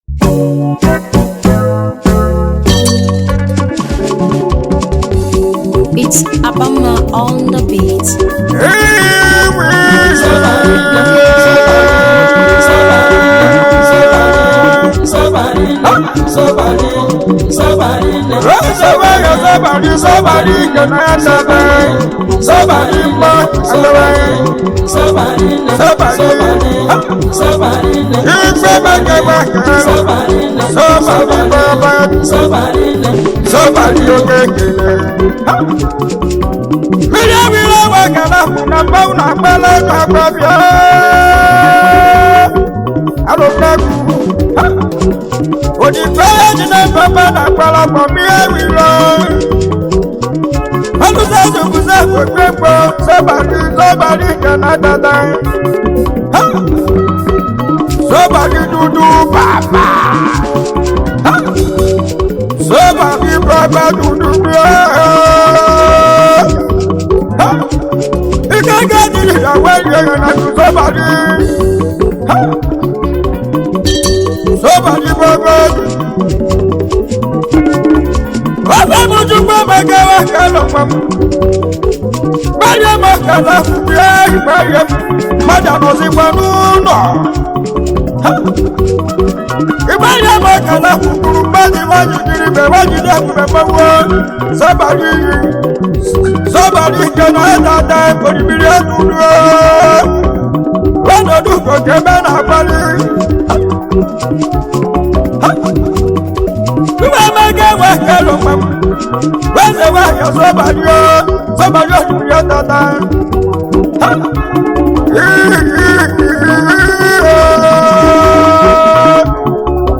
highlife track
highlife tune